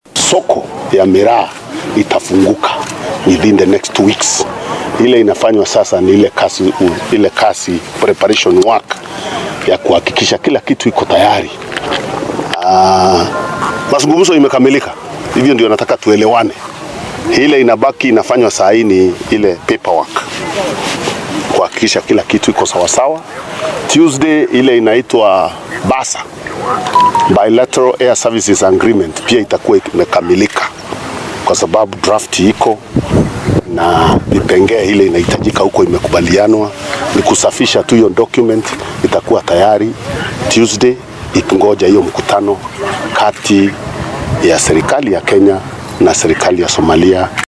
Waxaa sidatan maanta sheegay wasiirka wasaaradda beeraha ee dalka Peter Munya oo warbaahinta la hadlay.
Mar uu arrimahan ka hadlay ayuu yiri wasiirka beeraha ee Kenya.